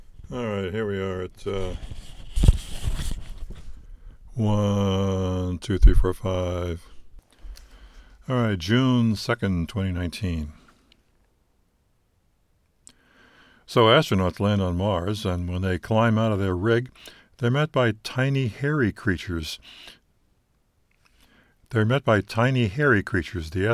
This is one of the sound tests I performed when I got it out of the box.
This is cut and no other effects.
This is the workup including dropping a book a couple of times, spilling coffee and a reading fluff.
Replace all the wood with my messy but quiet bedroom.